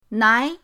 nai2.mp3